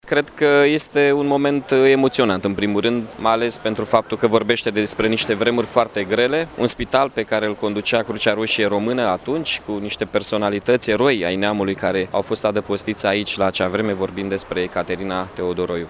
Astăzi, în prezenţa oficialităţilor locale, la Iaşi, au fost dezvelite două plăci care marchează importanţa şi rolul pe care le-a avut orașul în Primul Război Mondial.
Primarul Mihai Chirica: